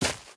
t_grass3.ogg